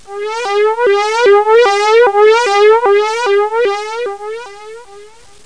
05077_Sound_SIRENA.mp3